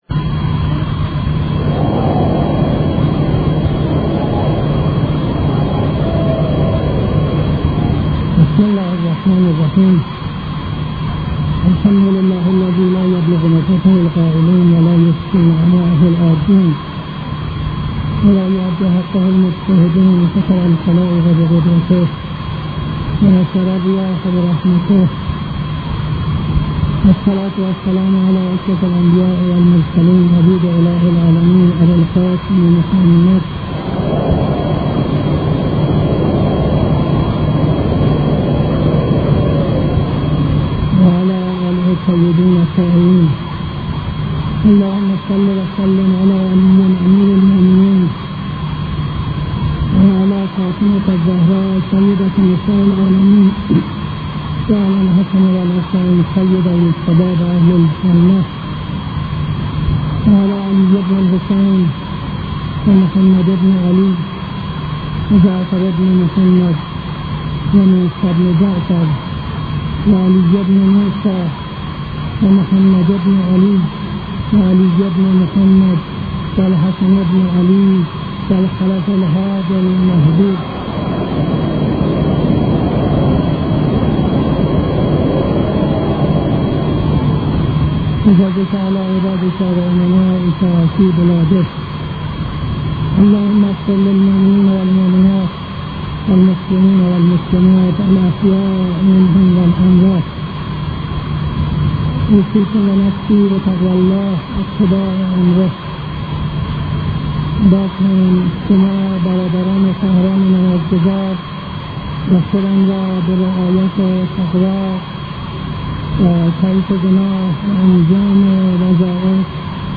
خطبه دوم نماز جمعه 12-02-72